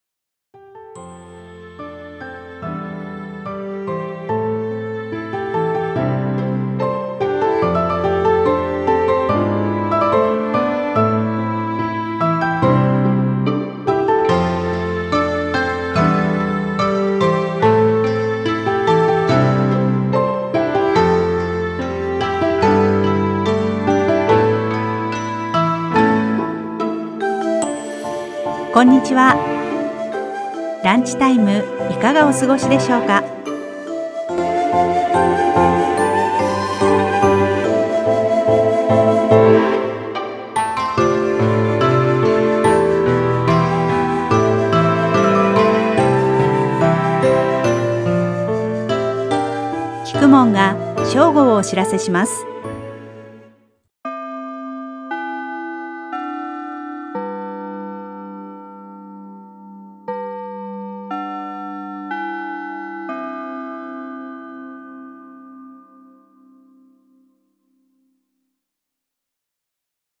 ランチタイムの時報 [その他のファイル／12.85MB] 録音音声